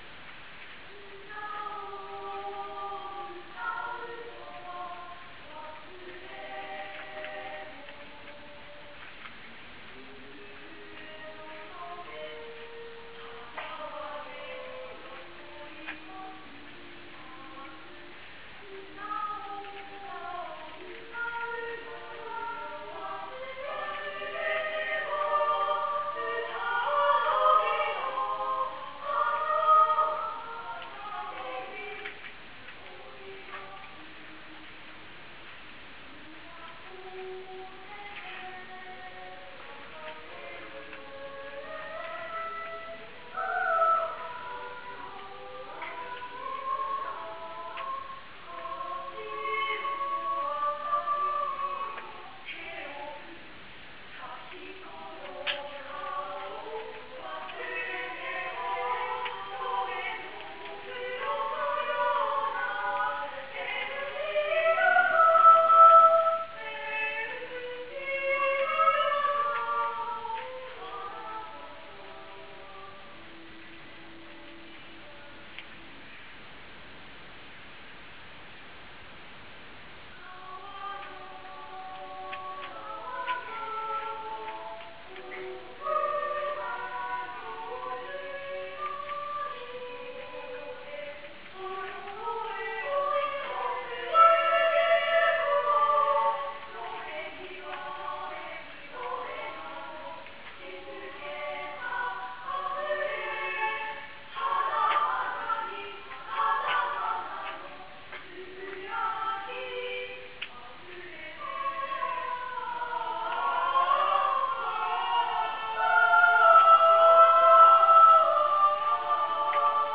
♪♪合唱部♪♪＜中津音楽会＞
昨日の合唱祭での歌声をぜひお聞きください。